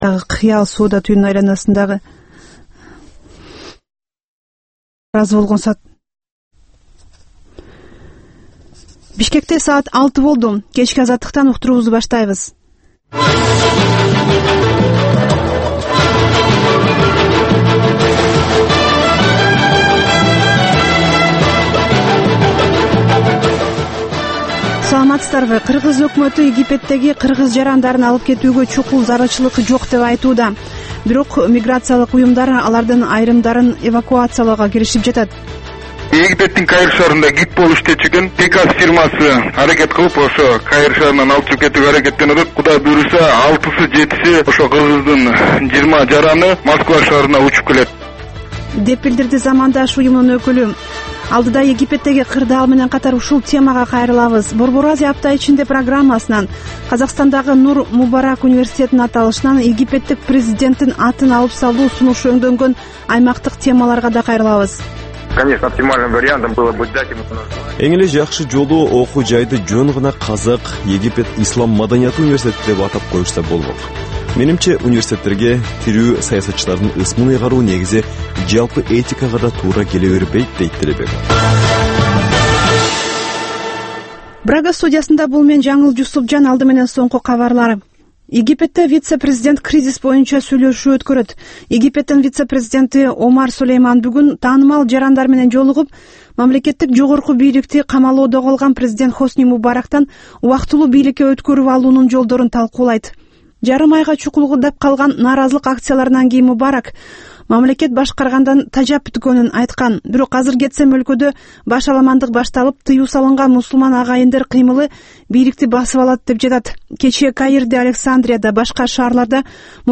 Кечки 6дагы кабарлар